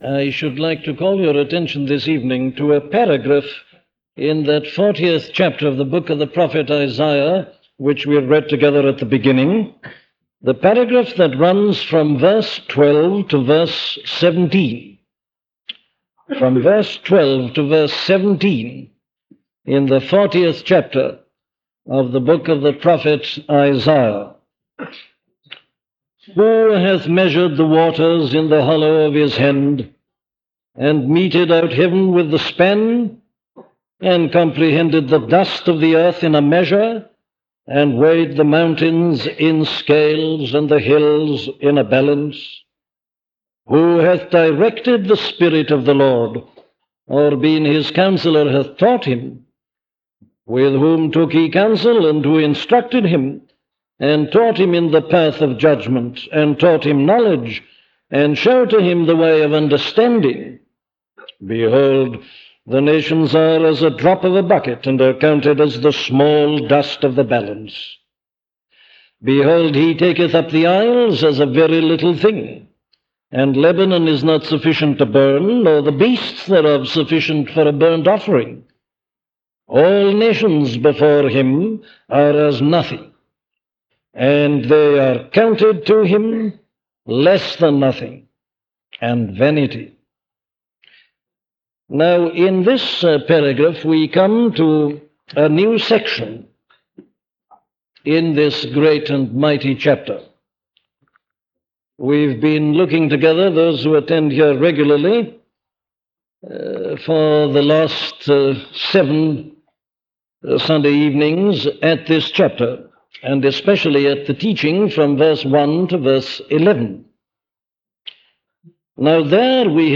Behold Your God - a sermon from Dr. Martyn Lloyd Jones
Listen to the sermon on Isaiah 40:12-17 'Behold Your God' by Dr. Martyn Lloyd-Jones
In this sermon on Isaiah 40:12–17 titled “Behold Your God,” Dr. Martyn Lloyd-Jones preaches that it is the assumption that God is like people.